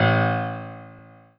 piano-ff-13.wav